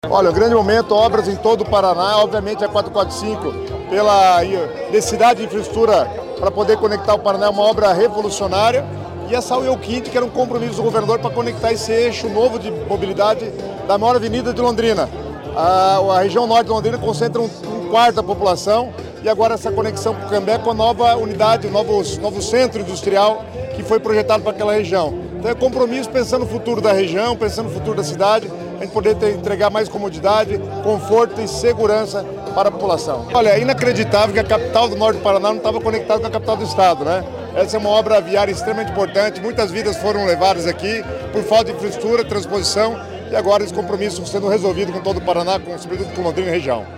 Sonora do secretário das Cidades, Guto Silva, sobre a duplicação da avenida Saul Elkind em nova Cidade Industrial de Londrina